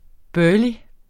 Udtale [ ˈbœːli ]